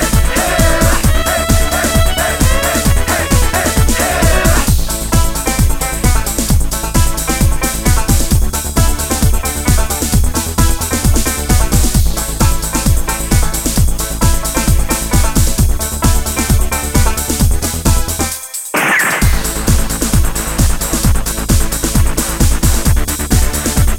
For Duet Duets 3:15 Buy £1.50